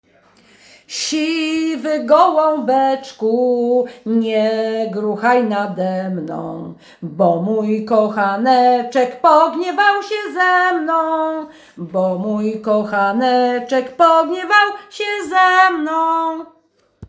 Nagranie współczesne